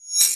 Boom-Bap SFX 34.wav